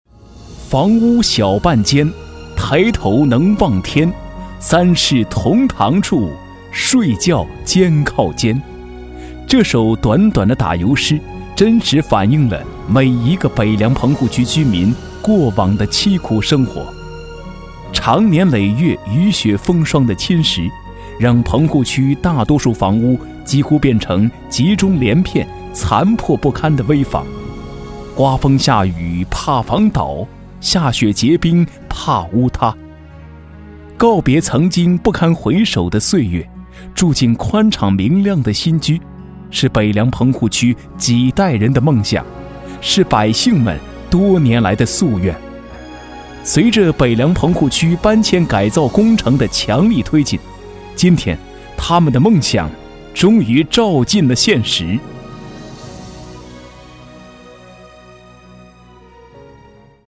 La entonación debe ser especial para el medio de la radio, para conseguir que el oyente no pierda la atención y tener gancho en su exposición.
Cuña de radio en chino
Locutor-varón-chino-17.mp3